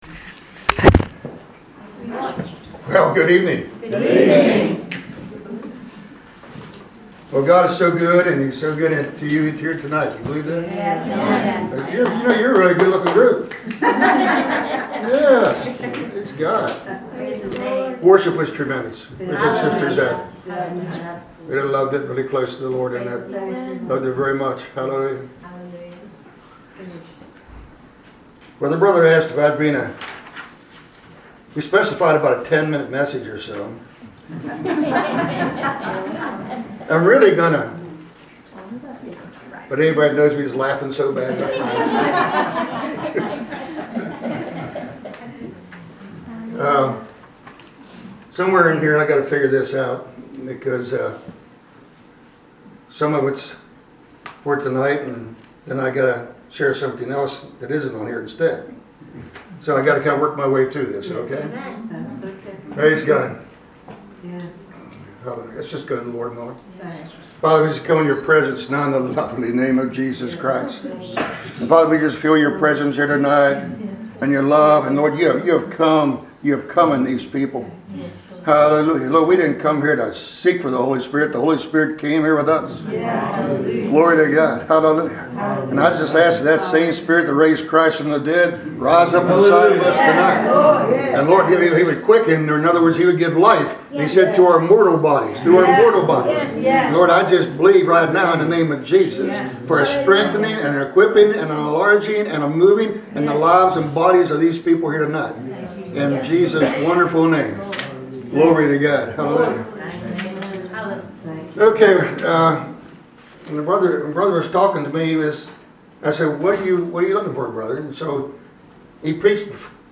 Special Service – Night of Encouragement for Intercessors